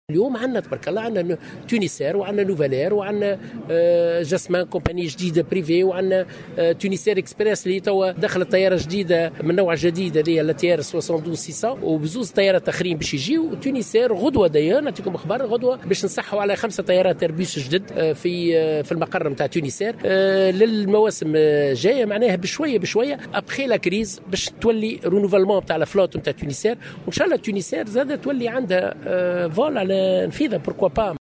أكد وزير السياحة ووزير النقل بالنيابة روني الطرابلسي اليوم الأربعاء 4 ديسمبر 2019 في تصريح للجوهرة "اف ام" خلال زيارته لمطار النفيضة أن شركة الخطوط التونسية ستوقع غدا الخميس عقدا لاقتناء 5 طائرات جديدة ايرباص جديدة.